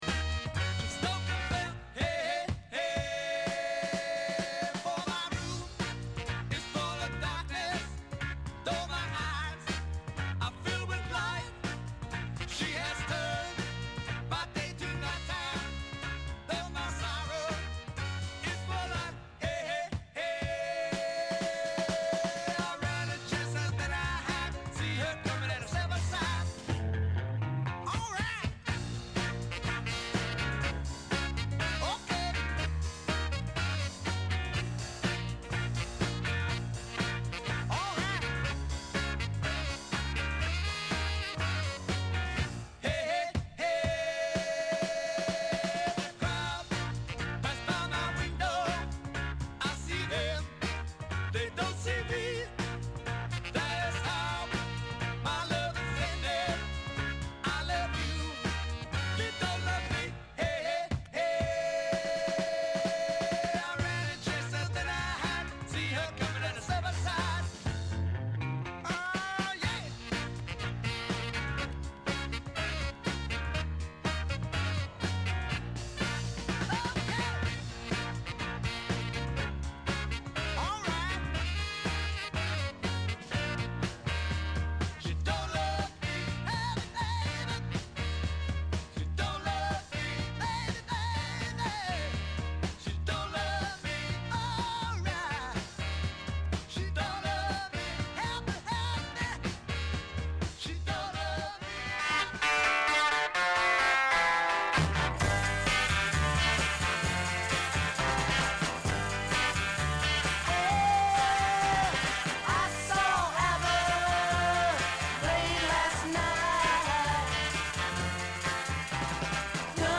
al Csoa Cox 18, via Conchetta 18 – Milano